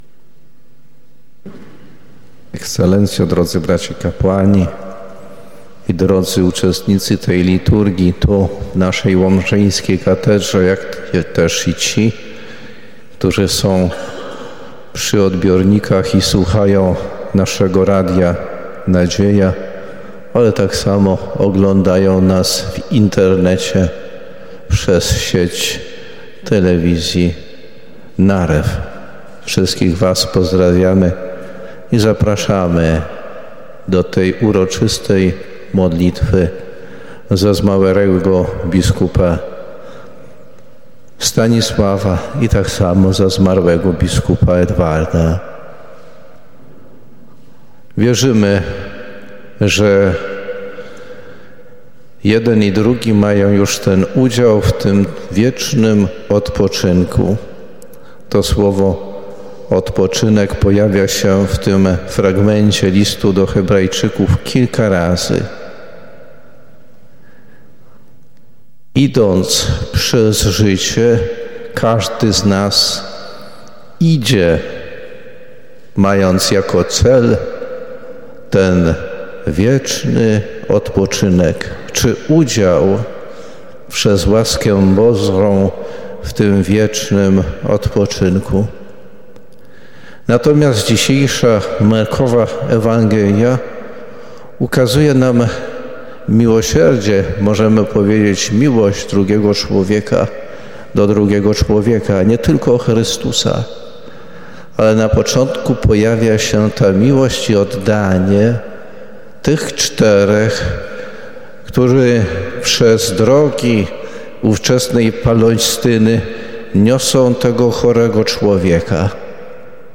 W piątek (17.01)  o godz. 18:00 w Katedrze pw. św. Michała Archanioła sprawowana była Msza św. w intencji biskupa seniora Stanisława Stefanka.
Homilia ks. bp. Janusza Stepnowskiego: